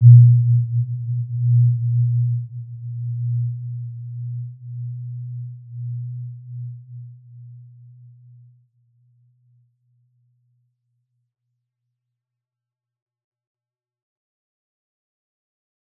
Warm-Bounce-B2-p.wav